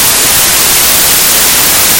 whitenoise.mp3